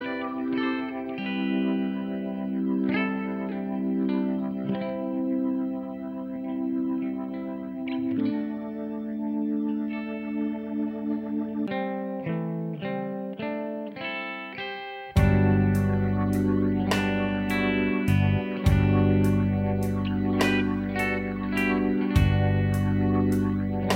With Intro R'n'B / Hip Hop 3:05 Buy £1.50